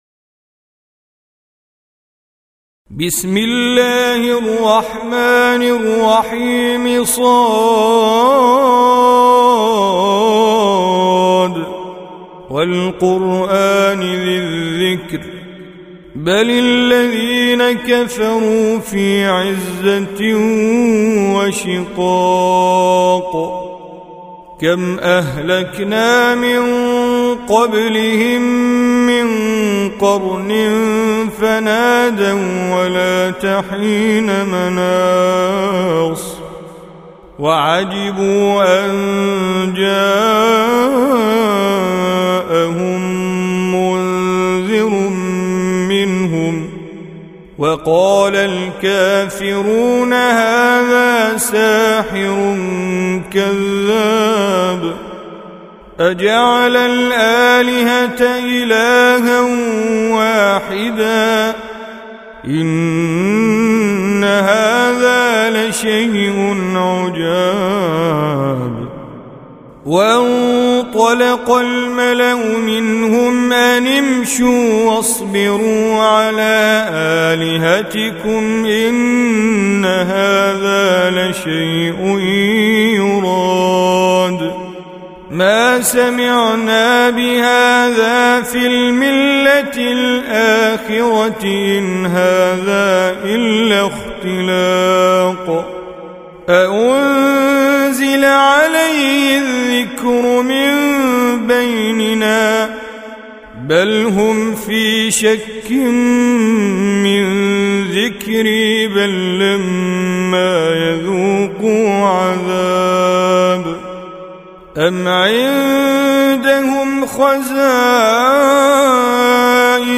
Surah Repeating تكرار السورة Download Surah حمّل السورة Reciting Mujawwadah Audio for 38. Surah S�d. سورة ص N.B *Surah Includes Al-Basmalah Reciters Sequents تتابع التلاوات Reciters Repeats تكرار التلاوات